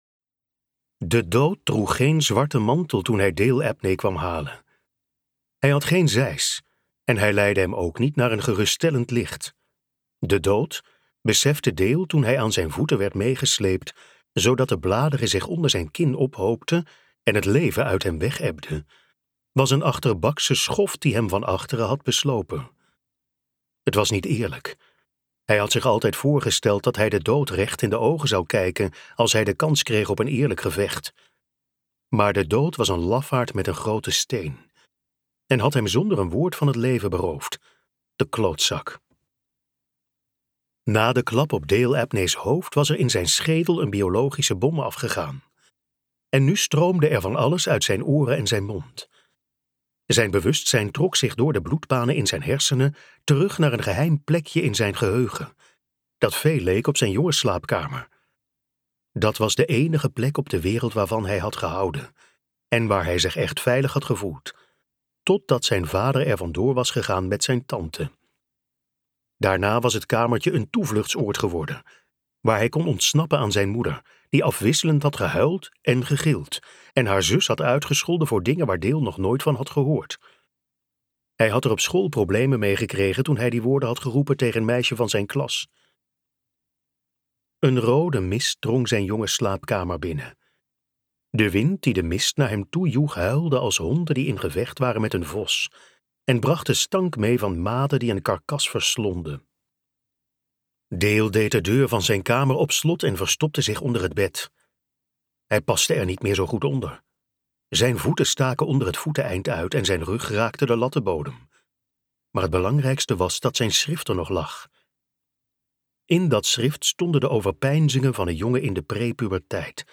De stalker luisterboek | Ambo|Anthos Uitgevers